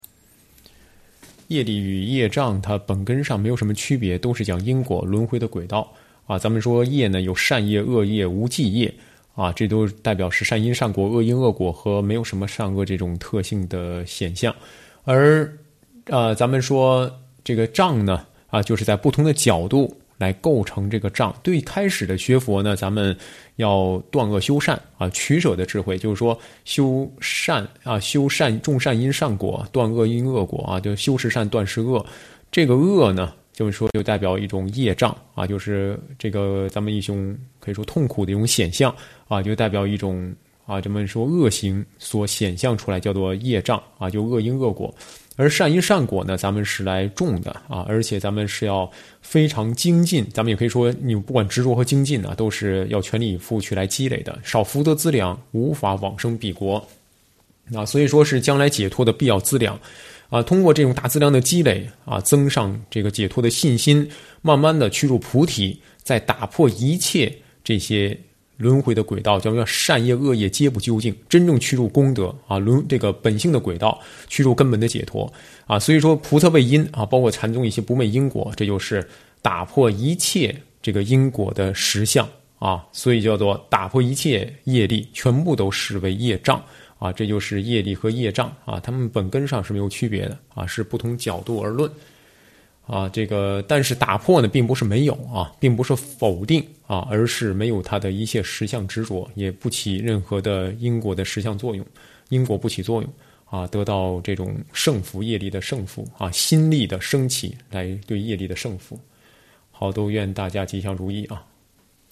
上师语音开示